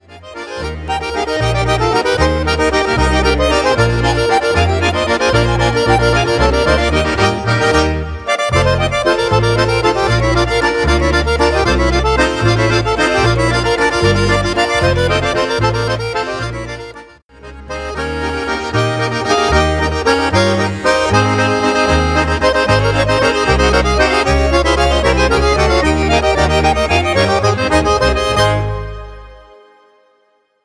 Ländler